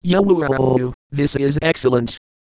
Worms speechbanks
excellent.wav